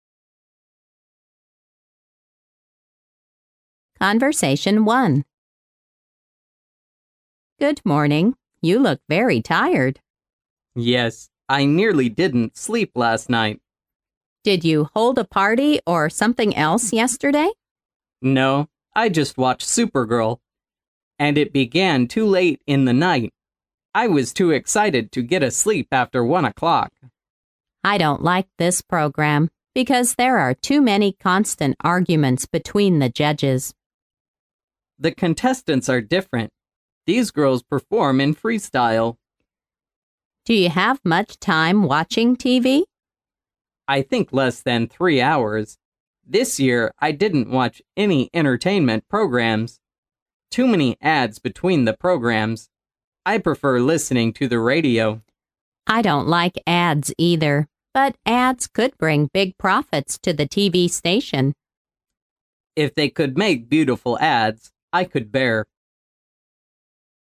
潮流英语情景对话张口就来Unit13：超级女声mp3